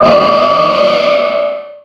Cri de Méga-Gardevoir dans Pokémon X et Y.
Cri_0282_Méga_XY.ogg